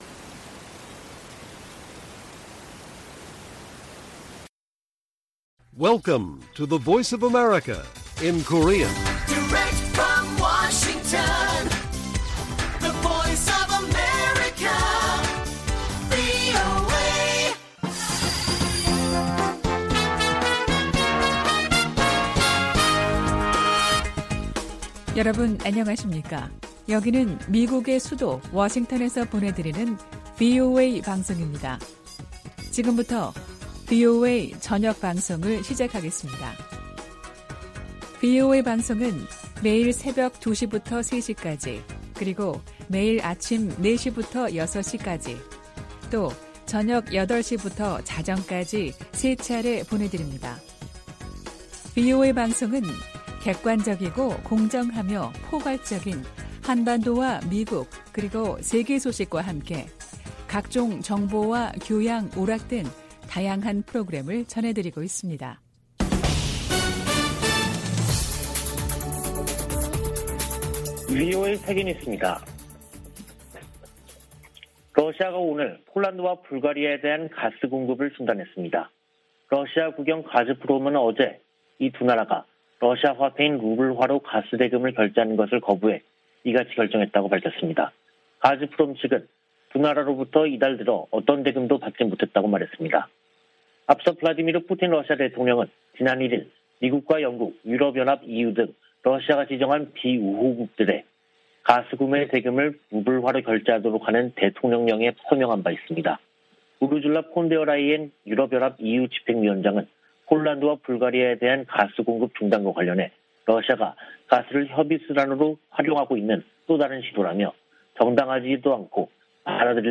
VOA 한국어 간판 뉴스 프로그램 '뉴스 투데이', 2022년 4월 27일 1부 방송입니다. 미 국무부는 '핵무력'을 언급한 김정은 북한 국무위원장의 25일 연설에 대해 북한이 국제 평화와 안보에 위협이 되고 있다고 지적했습니다.